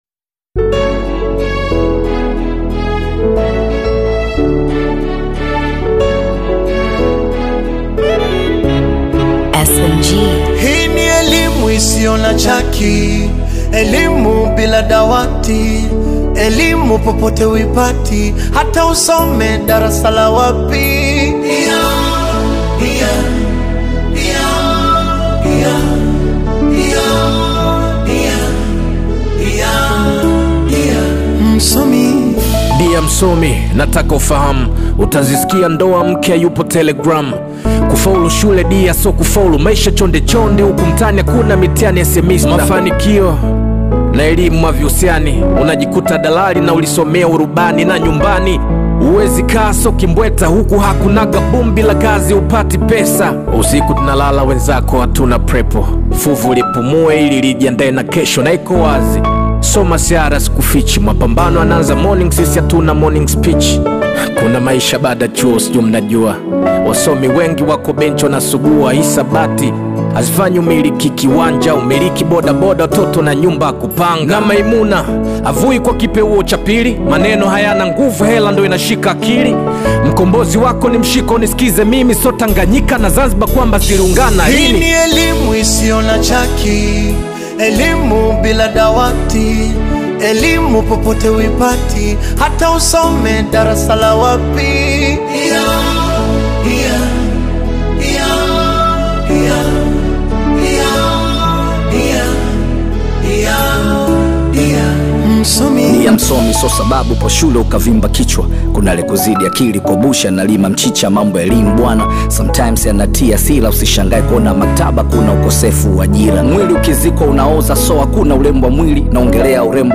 Tanzanian hip-hop